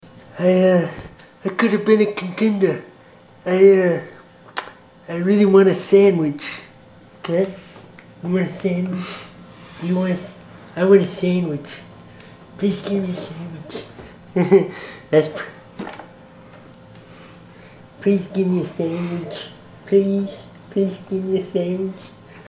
Guy from Kansas does Marlon Brando
Category: Comedians   Right: Personal
Tags: Comedian Marlon Brando Impersonation Marlon Brando impersonations The God Father